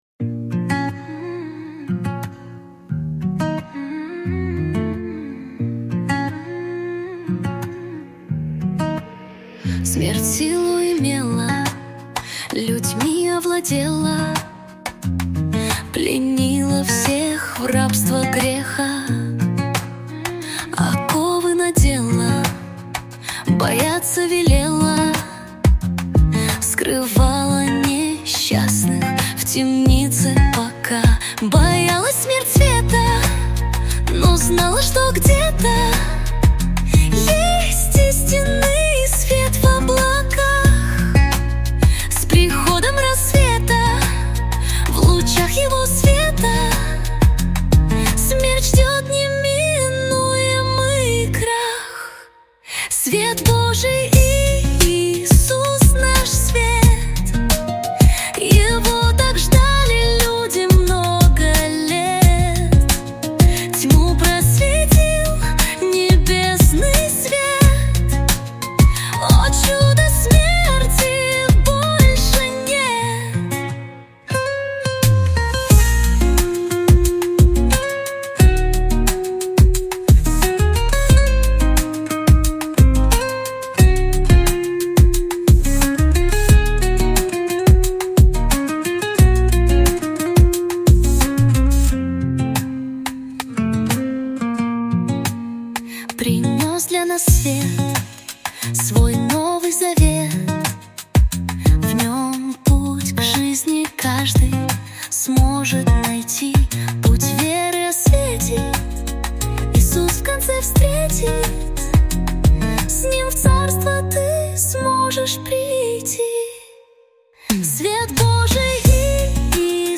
песня ai
264 просмотра 1050 прослушиваний 82 скачивания BPM: 89